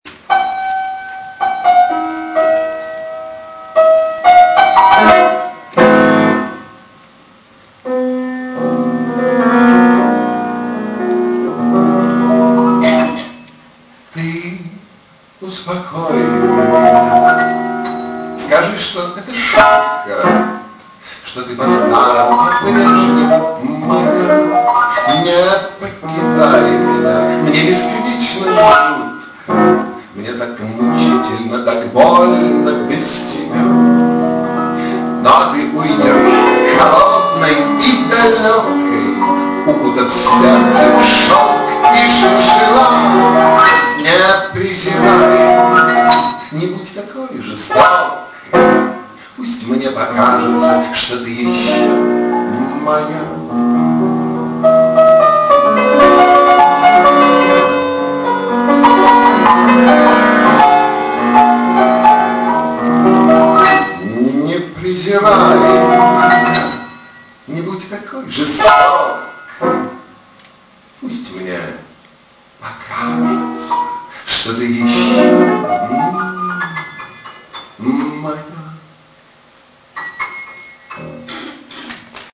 Романсы